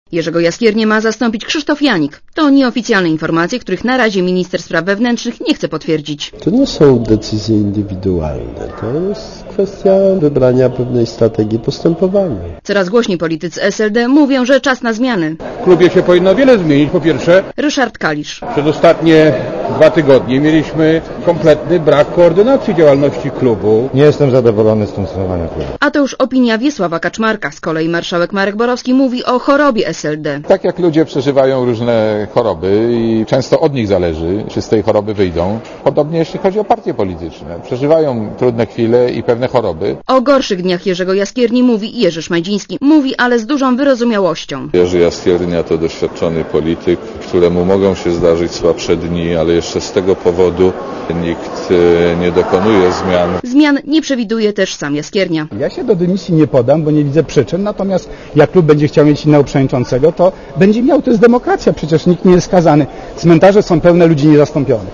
Posłuchaj relacji reportera Radia Zet (250kB)